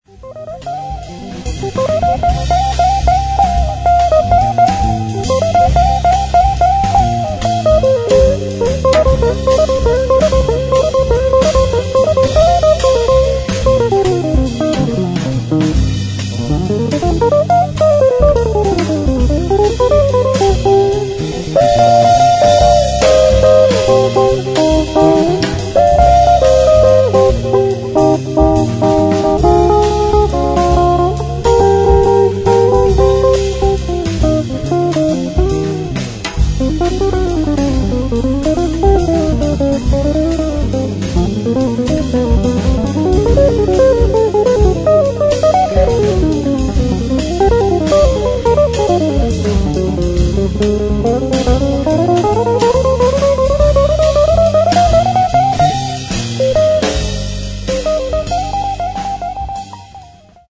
Registrato e missato presso il Midi Groove Studio (Napoli)
Chitarre
Basso elettrico
Batteria